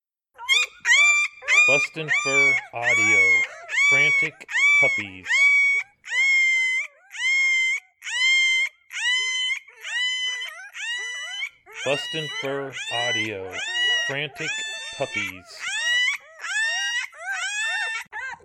3 week old Coyote pups crying in hunger and for mother.